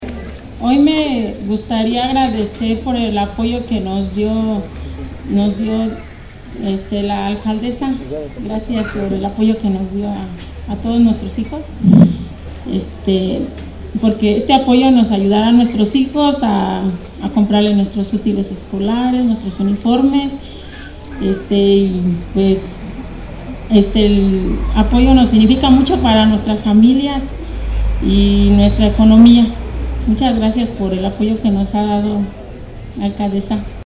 AudioBoletines
beneficiaria